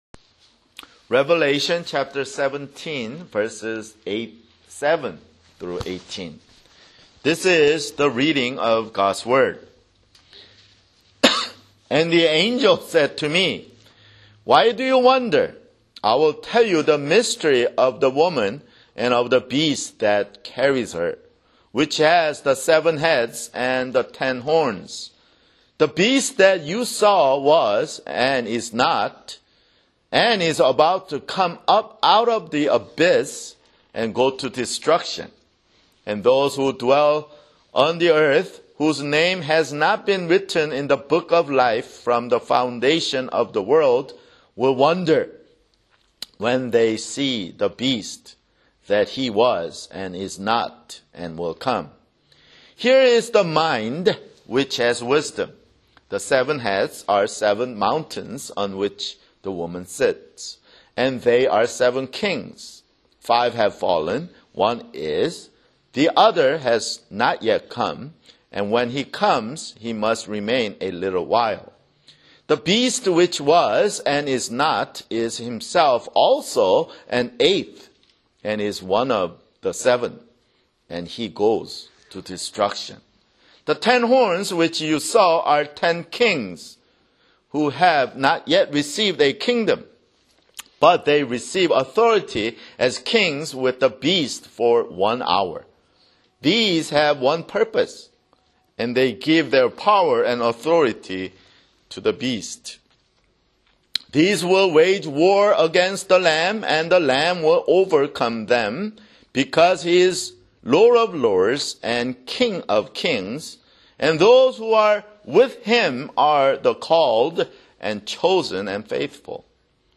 [Sermon] Revelation (67)